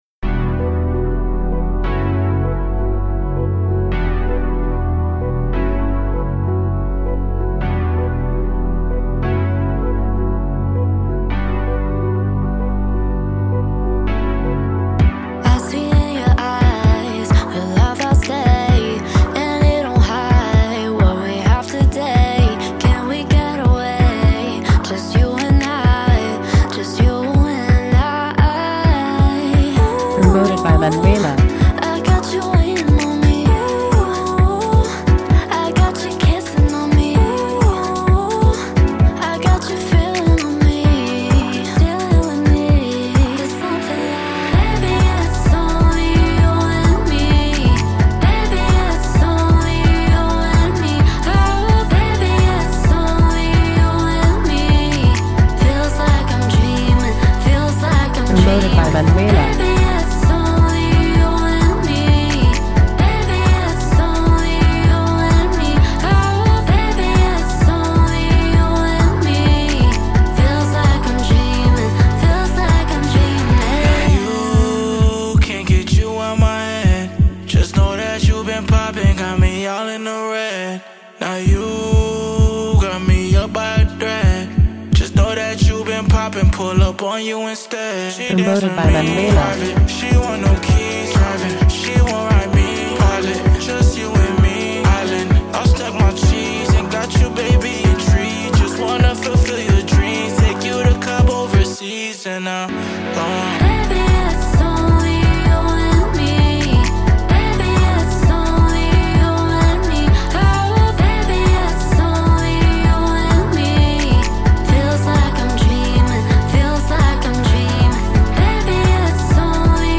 Radio Edit